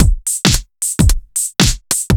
Index of /musicradar/off-the-grid-samples/110bpm
OTG_Kit 2_HeavySwing_110-C.wav